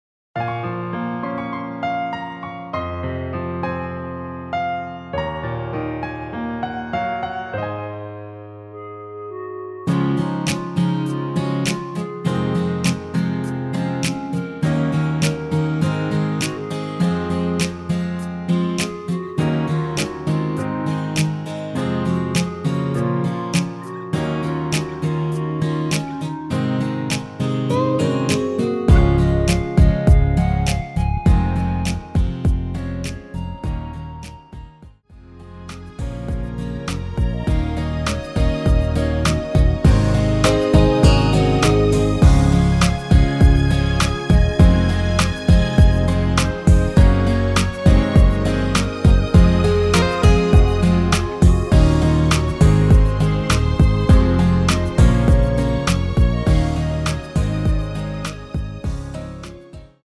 (-2) 내린 멜로디 포함된 MR 입니다.(미리듣기 참조)
Db
멜로디 MR이라고 합니다.
앞부분30초, 뒷부분30초씩 편집해서 올려 드리고 있습니다.
중간에 음이 끈어지고 다시 나오는 이유는